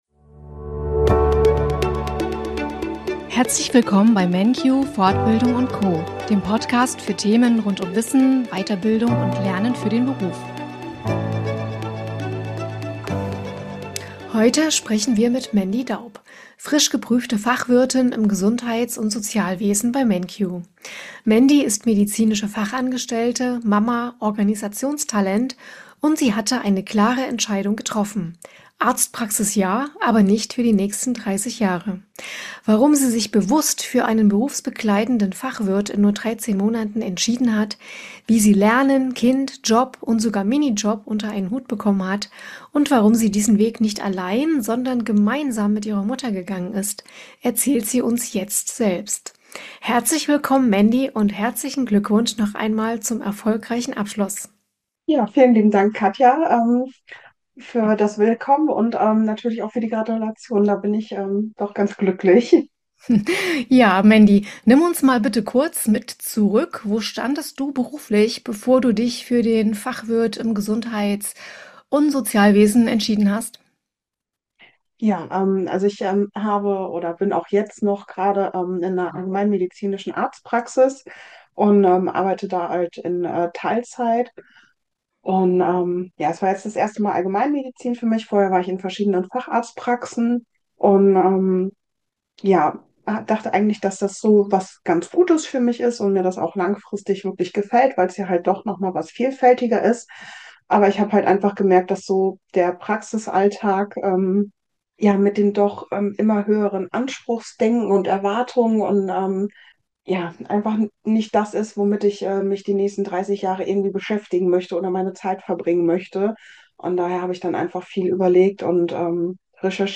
Im Interview spricht sie über Motivation, Lernen mit Kind und ihre beruflichen Ziele.